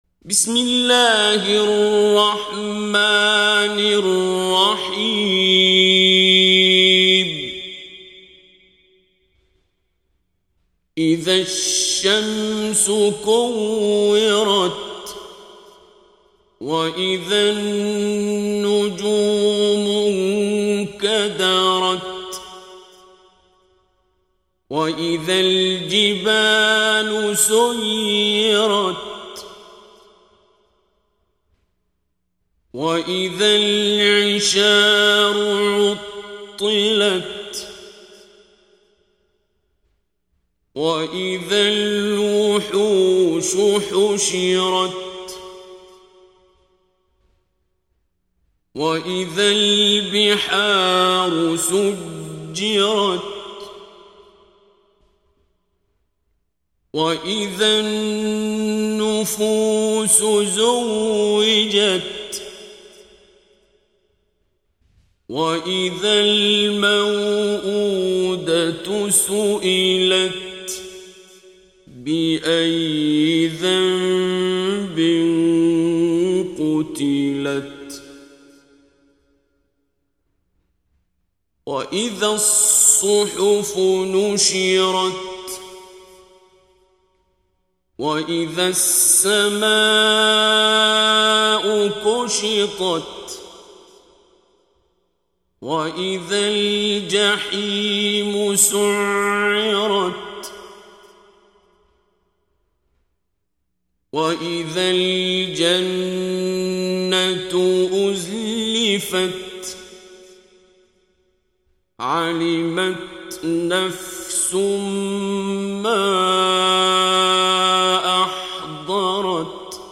ترتیل کل قرآن کریم با صدای استاد شیخ عبدالباسط عبدالصمد به تفکیک سوره های قرآن، با رعایت کامل قواعد تجویدی تقدیم مخاطبان قرآنی